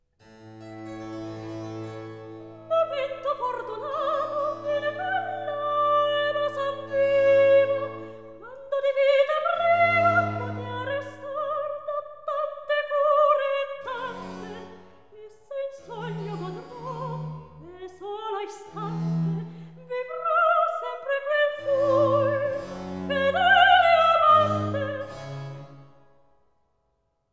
Recitativo